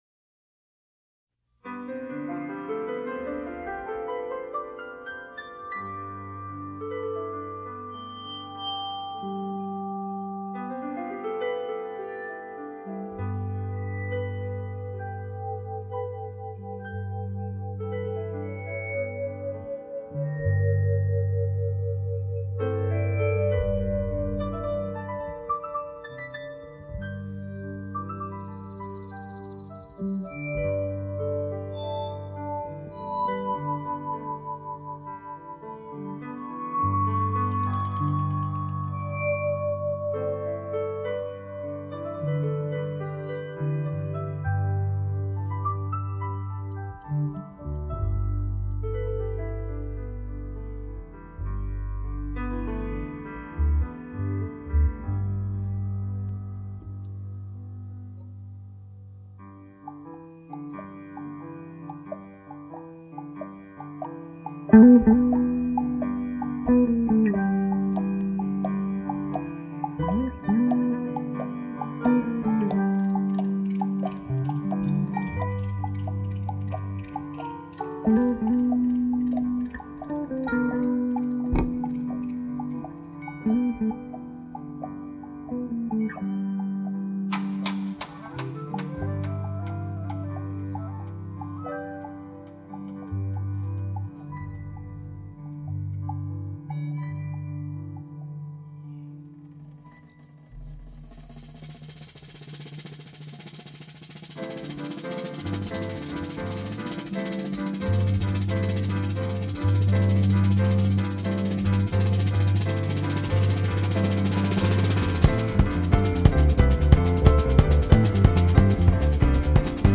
Never before released, this concert performed circa 1975